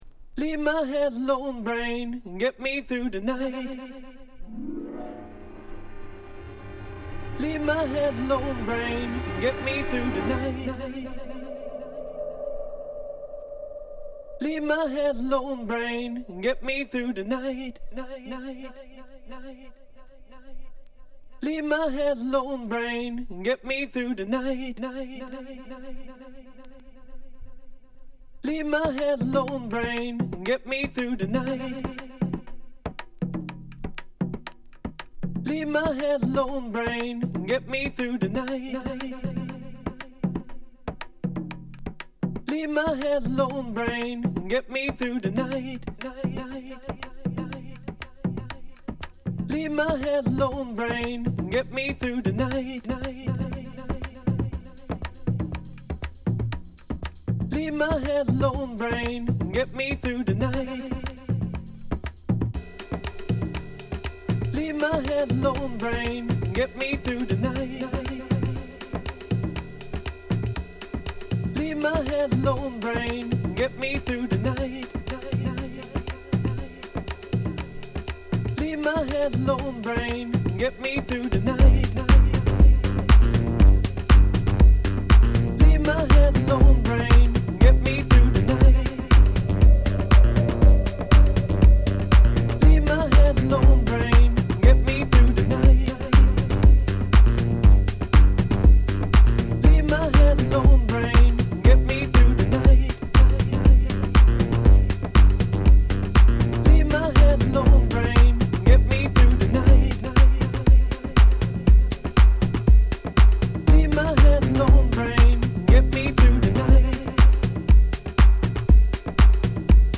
全曲フロア・キラー！！
＊試聴はA→B1→B2です。